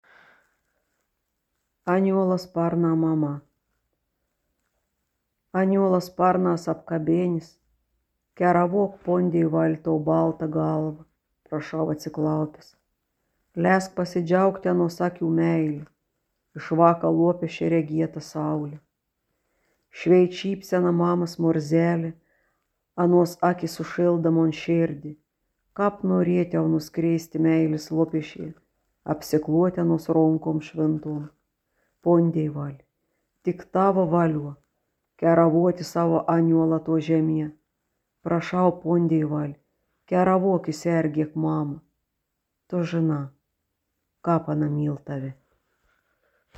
Įgarsinimai lietuvių kalba
Žemaitiška tarmė
zemaitiska-tarme-esu.mp3